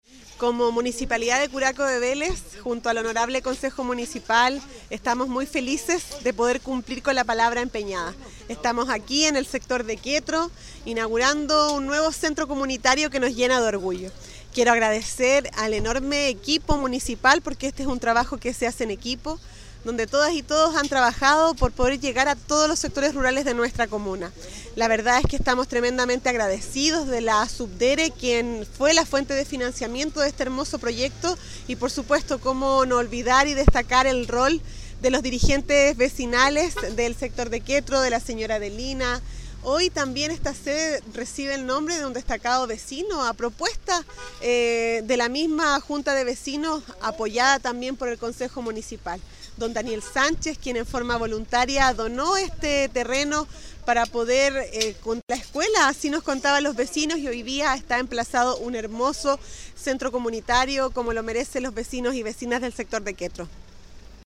Respecto a esta actividad la alcaldesa Javiera YÁñez indicó: